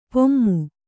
♪ pummu